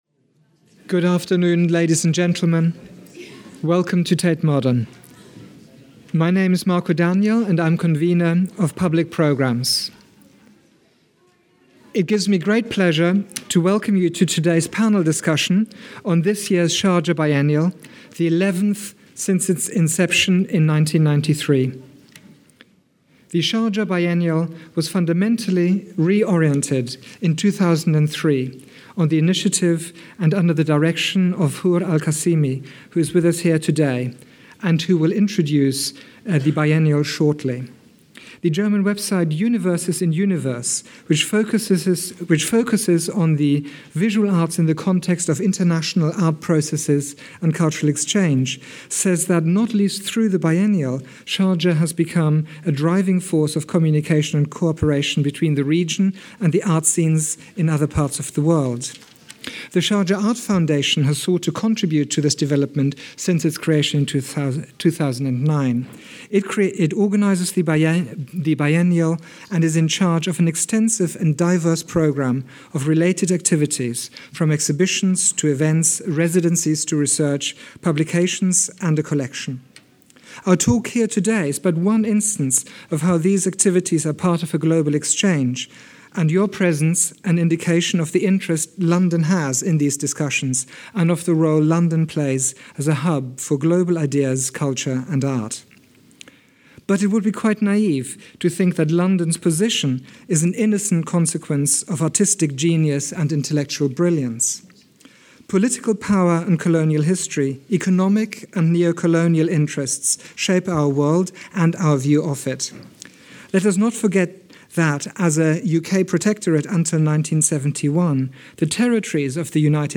Towards a new cultural cartography – audio coverage of past Tate Modern conference
A panel discussion about Sharjah Biennial 11: Introduction and morning session